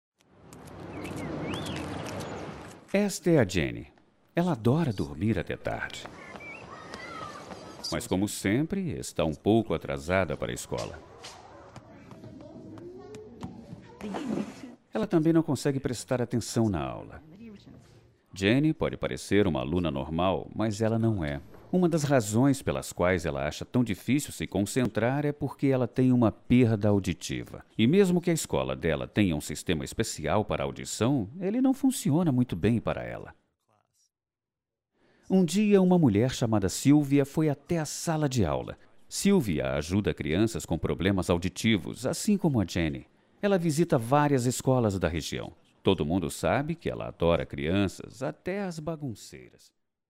Masculino
Narração de vídeo/documentário publicitário.
Voz Madura